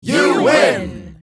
Download Free Success Sound Effects | Gfx Sounds
Crowd-shouts-you-win.mp3